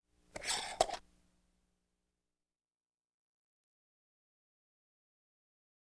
Mason Jar Open Sound Effect
Download a high-quality mason jar open sound effect.
mason-jar-open.wav